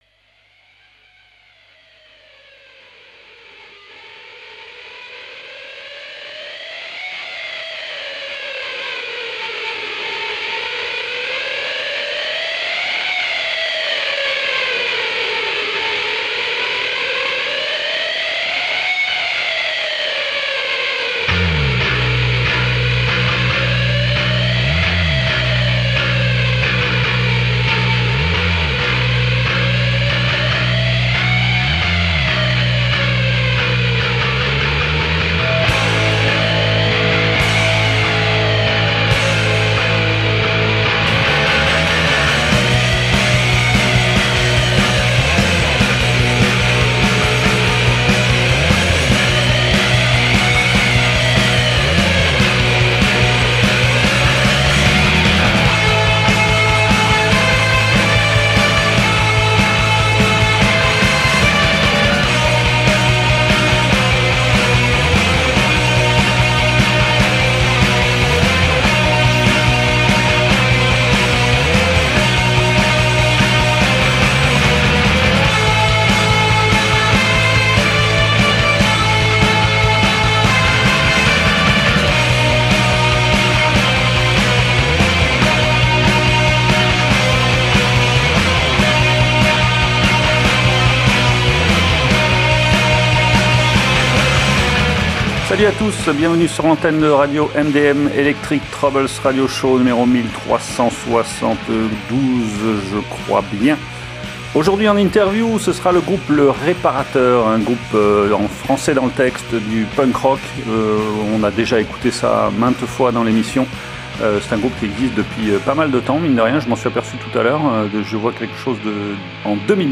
Une guitare, une batterie et deux grandes gueules pour chanter l’amer constat d’une société pantouflarde plus préoccupée par son profit personnel et son apparence que par les vraies valeurs : le sexe, la drogue et le rock’n’roll.